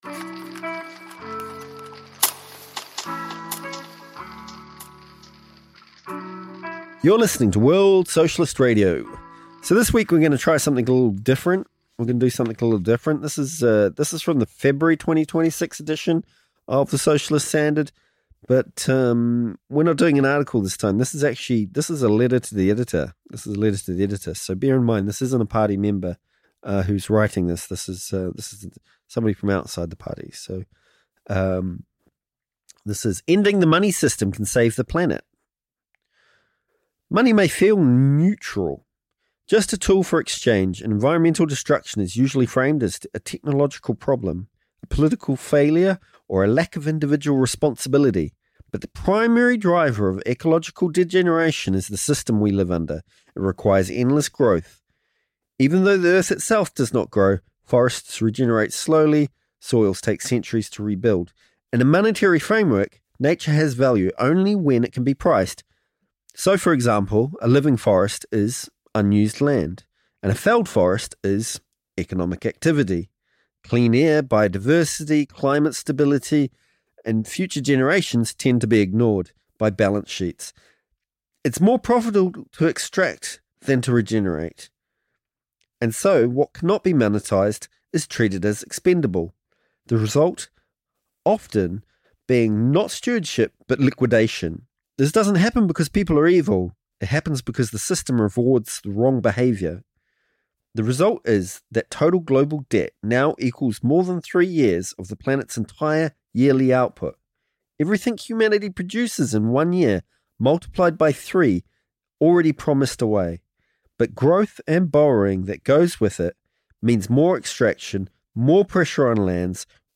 A letter to the editor.
Featuring music: 'Pushing P (Instrumental)' by Tiga Maine x Deejay Boe.